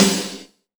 Index of /90_sSampleCDs/EdgeSounds - Drum Mashines VOL-1/M1 DRUMS
MSNARE 1.wav